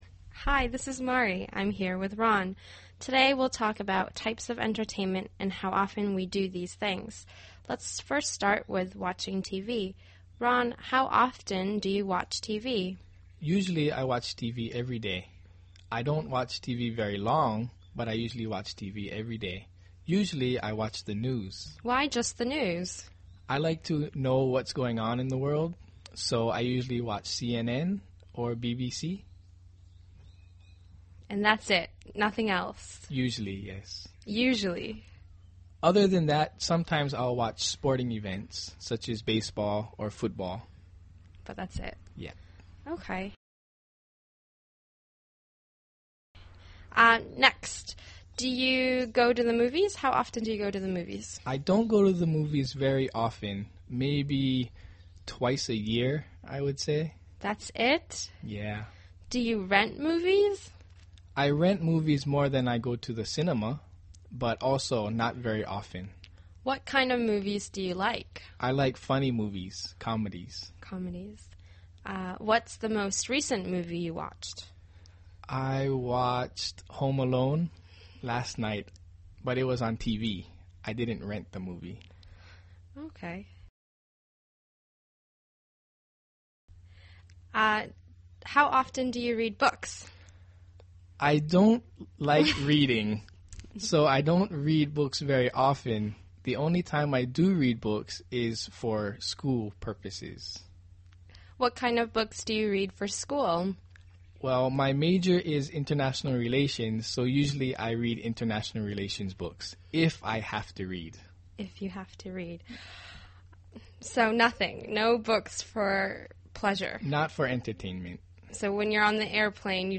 英语初级口语对话正常语速10：Ron的媒体选择（mp3+lrc）